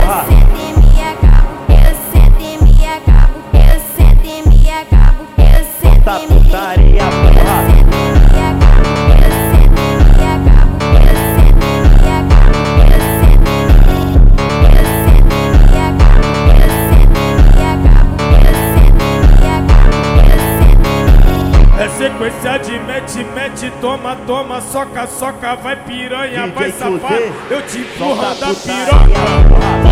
Жанр: Фанк
# Baile Funk